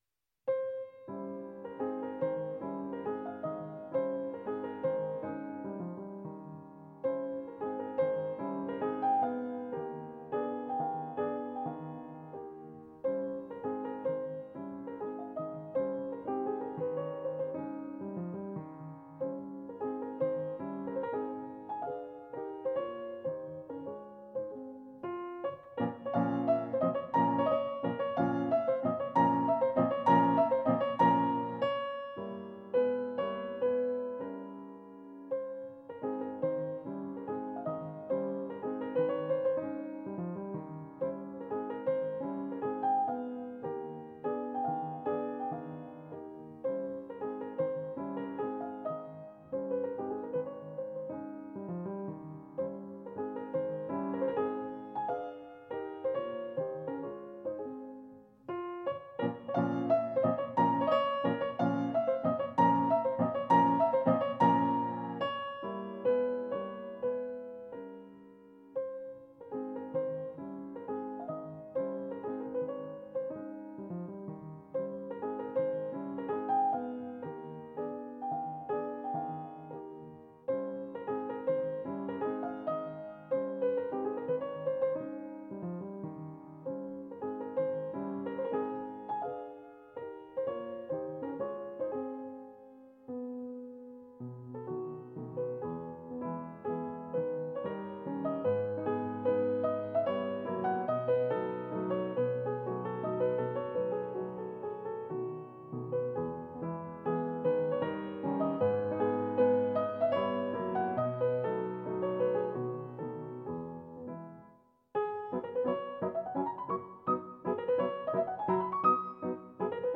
Piano version
Piano  (View more Advanced Piano Music)
Classical (View more Classical Piano Music)